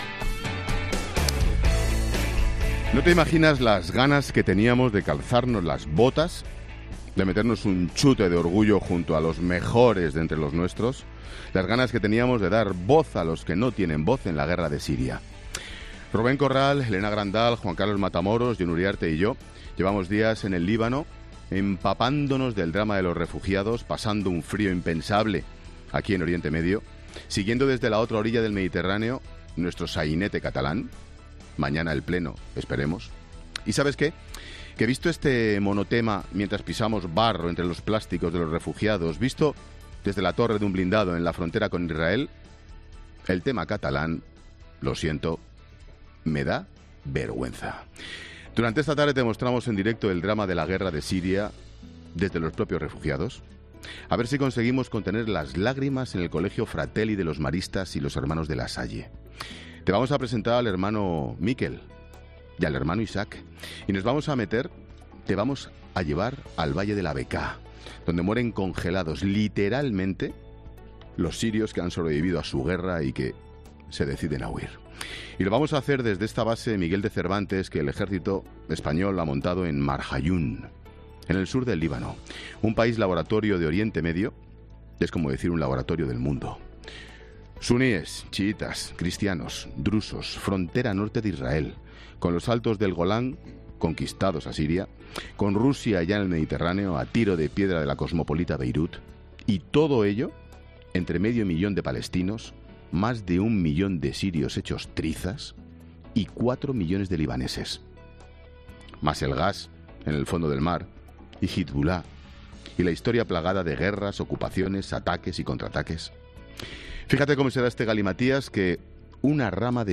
Monólogo de Expósito
El comentario de las 16h de Ángel Expósito desde Líbano.